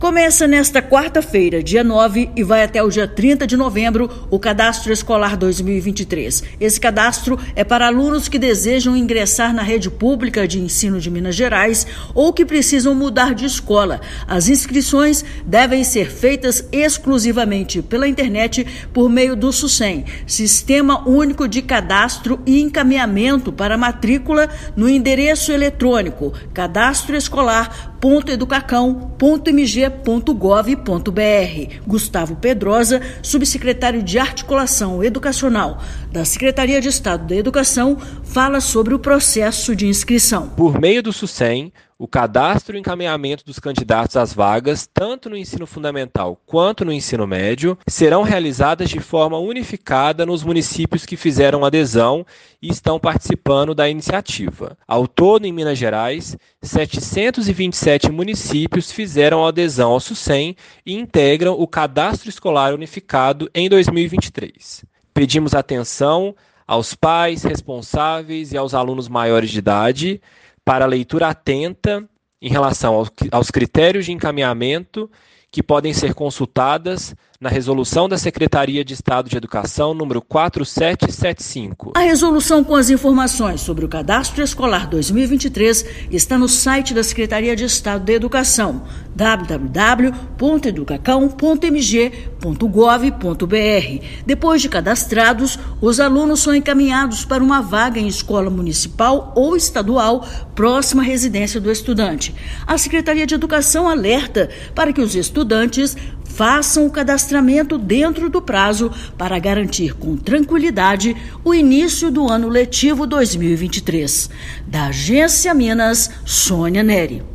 [RÁDIO] Cadastro Escolar 2023 da rede pública de ensino de Minas Gerais começa nesta quarta-feira (9/11)
Estudantes que querem ingressar na rede pública ou precisam mudar de escola devem se inscrever até o dia 30/11. Ouça matéria de rádio.